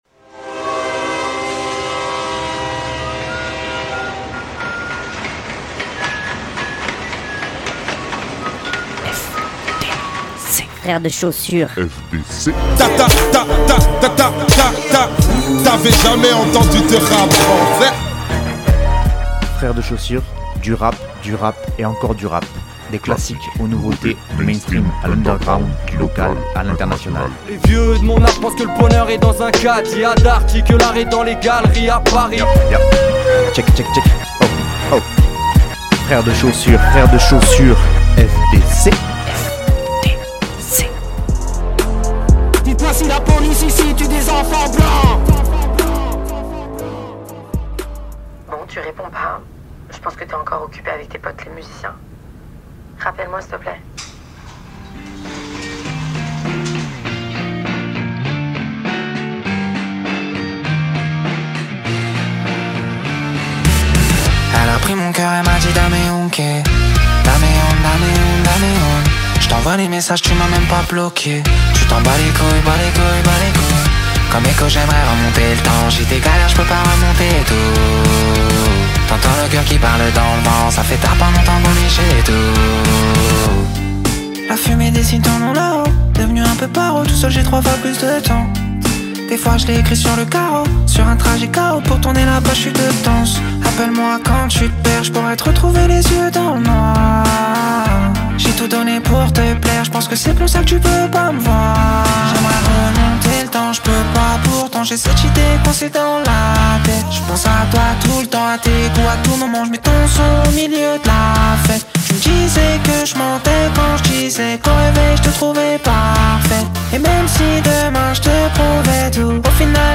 Que le rap ici.